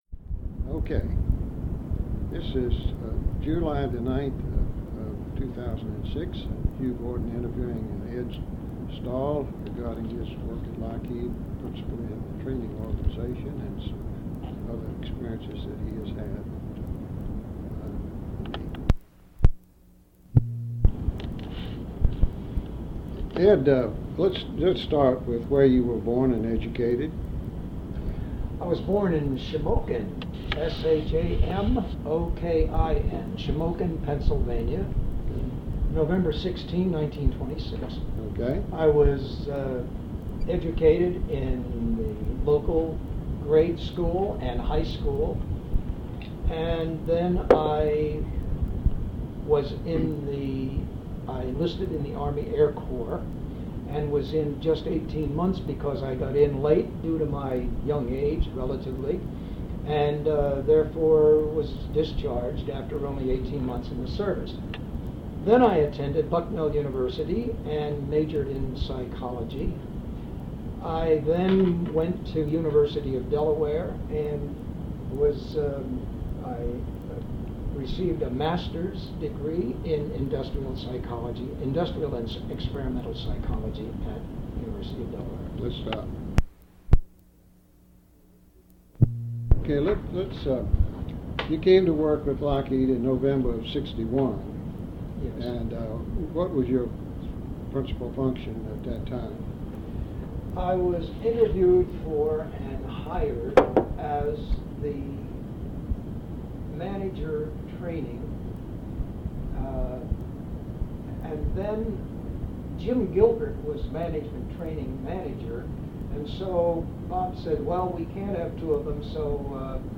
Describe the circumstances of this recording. Sound recording digitized from audiocassette of a telephone interview